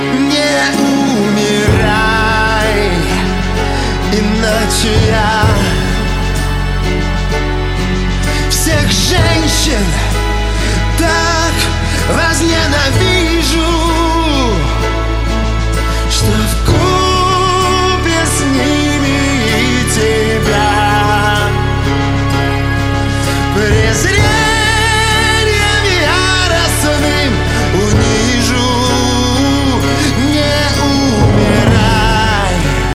• Качество: 128, Stereo
поп